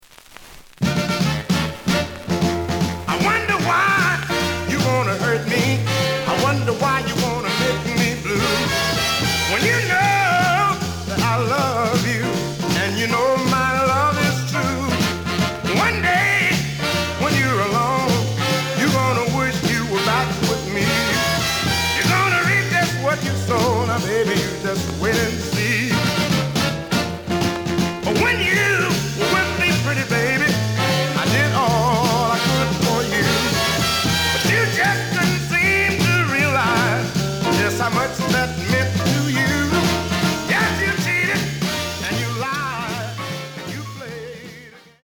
The audio sample is recorded from the actual item.
●Format: 7 inch
●Genre: Blues